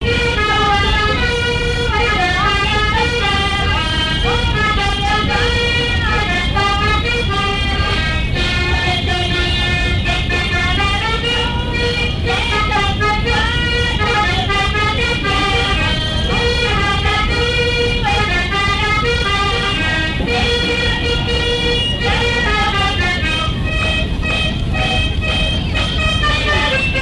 Usage d'après l'analyste gestuel : danse
lors d'une kermesse
Pièce musicale inédite